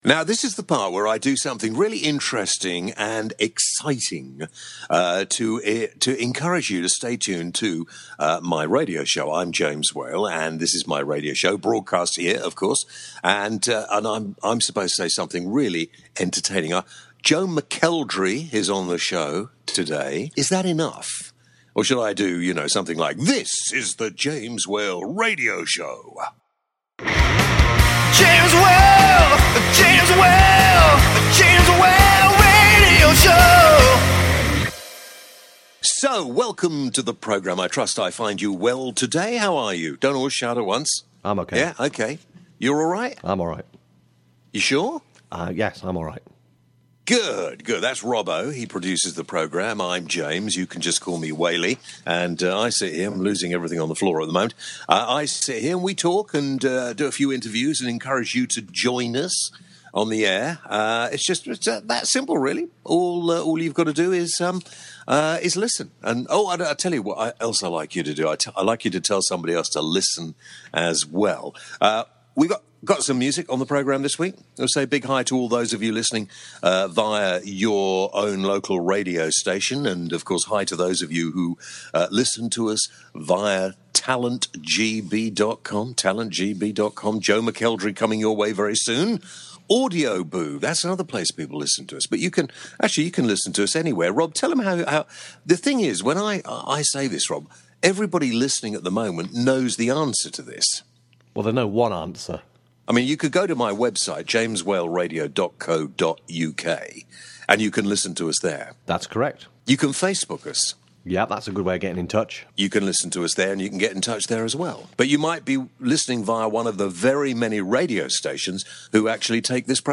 On this show: James chats to Joe McElderry about the music business and his current tour.